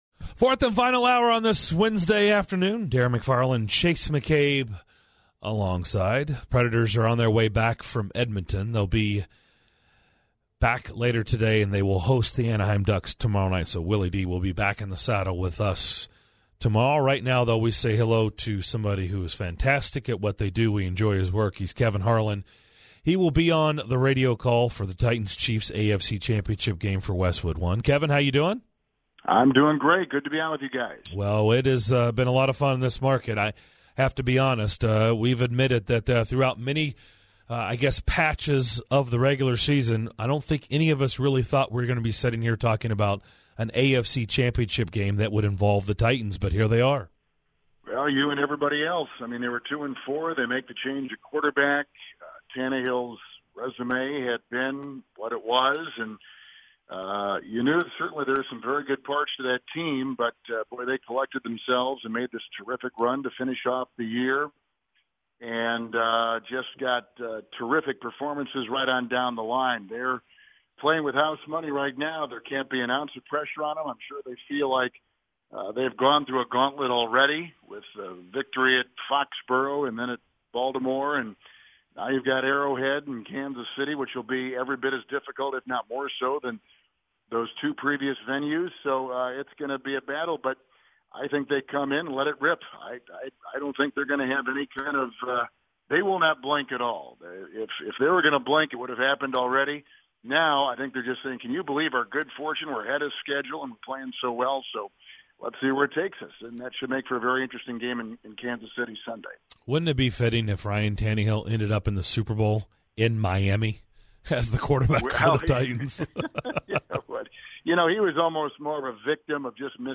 Legendary broadcaster Kevin Harlan joins DDC to talk about the Chiefs-Titans match-up that he will be on the call for on Westwood One.